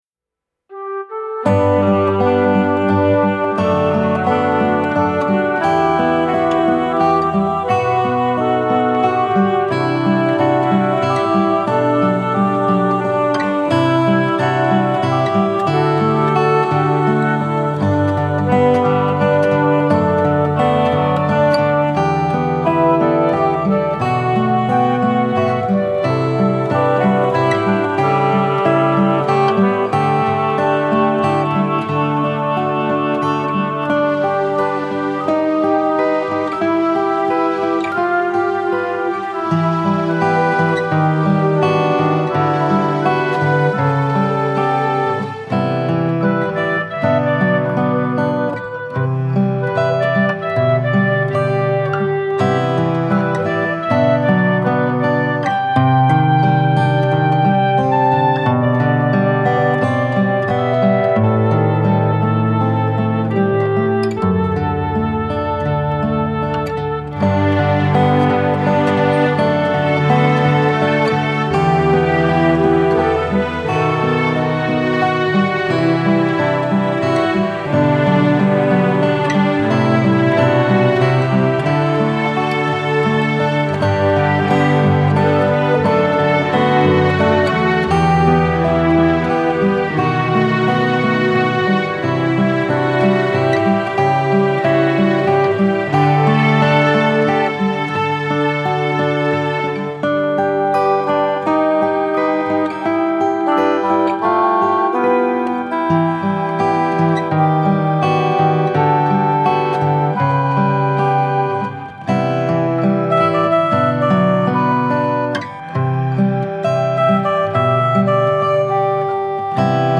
It is an arrangement of a guitar instrumental piece, which I named Farewell.
FAREWELL-1/20/13 Guitar Instrumental